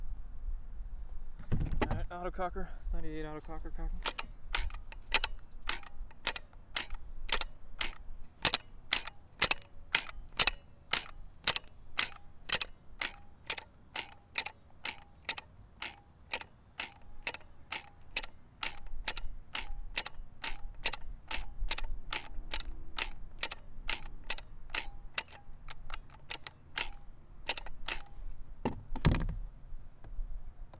autococker_cock_raw_01.wav